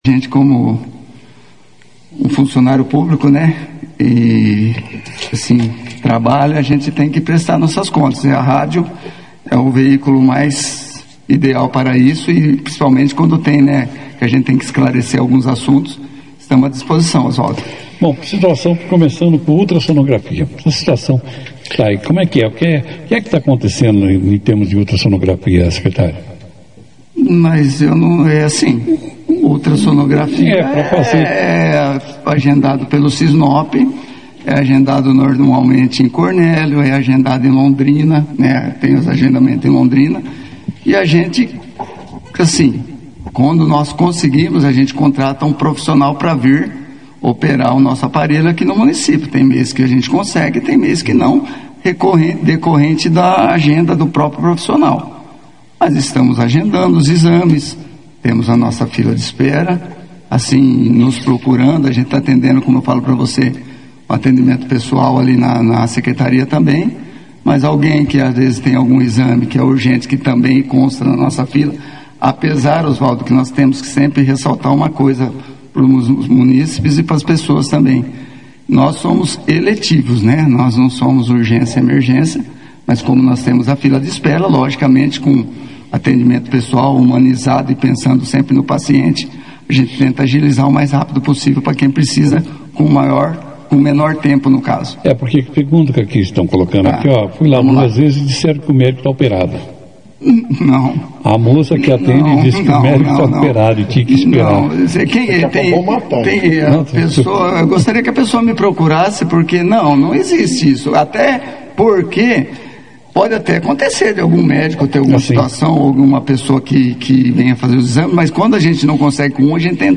O Secretário de Saúde de Bandeirantes, Alexandro Beretta (foto), esteve presente na 2ª edição do Jornal Operação Cidade, nesta segunda-feira, 9 de junho. Durante a entrevista, Beretta falou sobre a importância da vacinação contra a gripe, especialmente neste período com muitos casos de Síndromes Respiratórias Agudas Graves (SRAGs) — situação que levou o Governo do Estado a declarar um alerta de urgência. O secretário também respondeu a diversos questionamentos dos usuários, esclarecendo dúvidas e fornecendo informações à população.